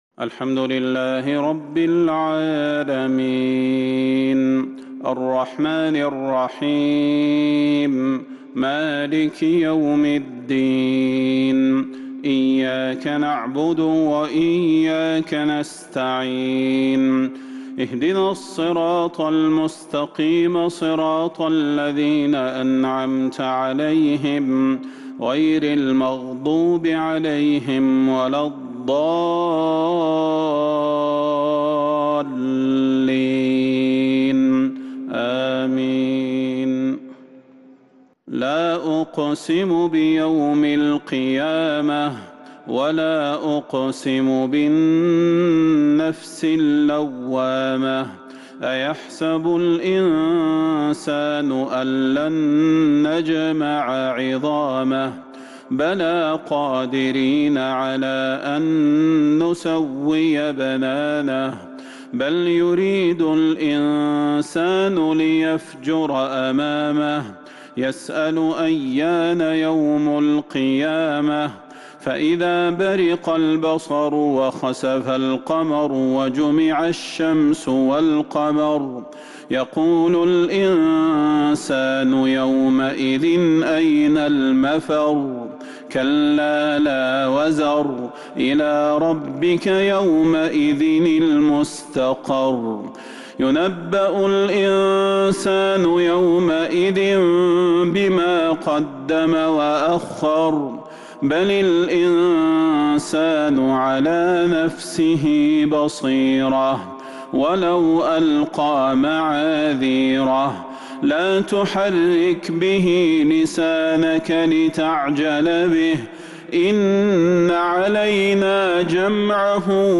عشاء الخميس 2-2-1443هـ سورة القيامة |Isha prayer from Surat Al-Qiyamah 9-9-2021 > 1443 🕌 > الفروض - تلاوات الحرمين